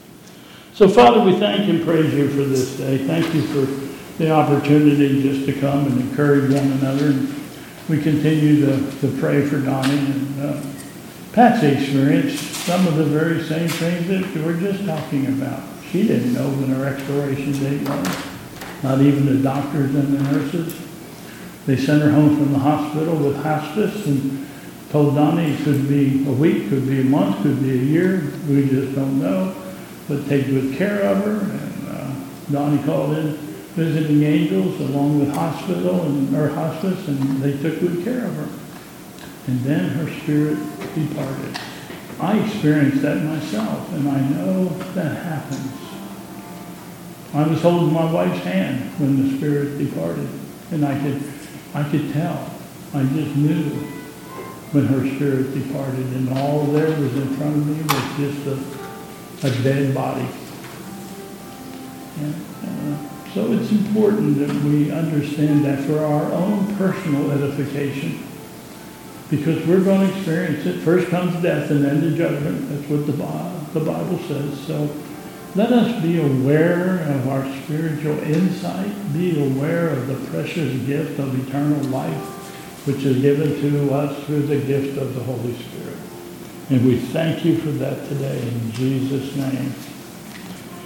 (01:30) - Closing Prayer